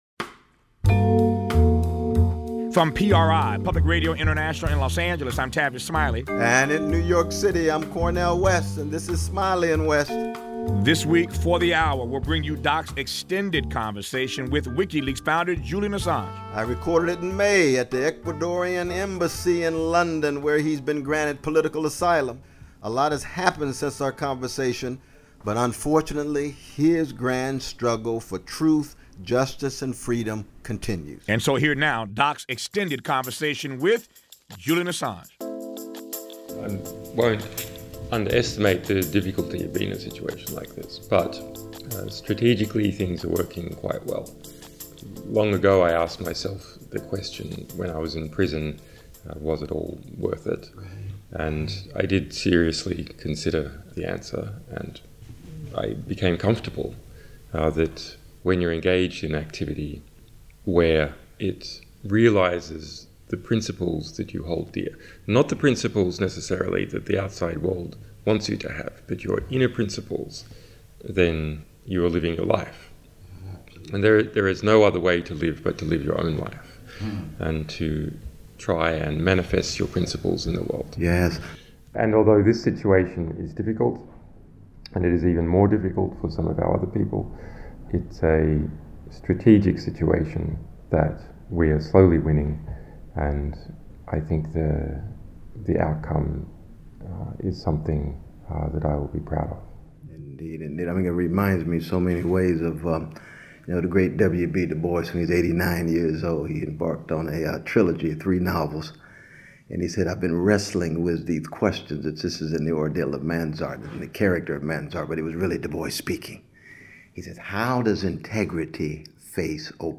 Here’s a remastered version of Cornel West’s interesting conversation with Julian Assange, recorded last May at the Ecuadorian Embassy in London. The ‘blues man’ wants to talk about W. B. Du Bois, R. H. Tawney, Margaret Fuller, Martin Luther King, Jr., Hannah Arendt, Abraham Heschel and Plato, and Assange wants to talks about Google, the rule of law, Bradley Manning and Wikileaks. They both like talking about courage, and appropriately so.
cornel-west-and-julian-assange-in-conversation.mp3